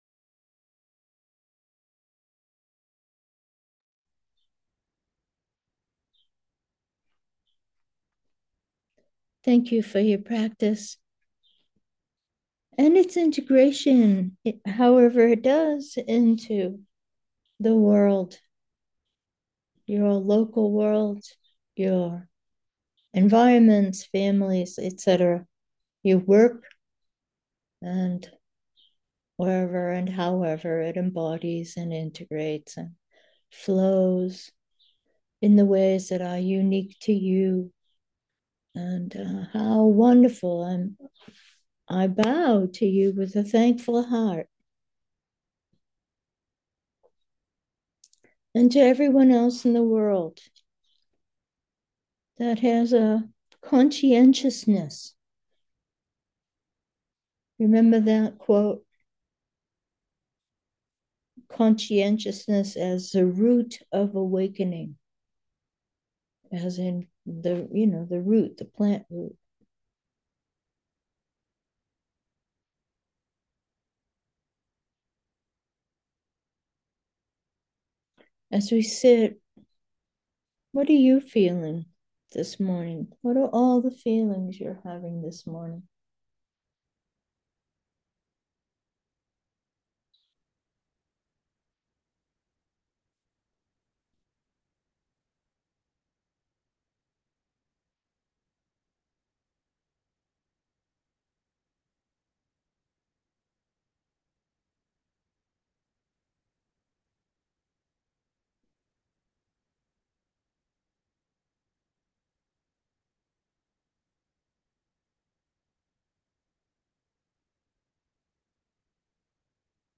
Meditation: directly discover 1